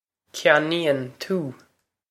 ceannaíonn kyan-een too
Pronunciation for how to say
This is an approximate phonetic pronunciation of the phrase.
This comes straight from our Bitesize Irish online course of Bitesize lessons.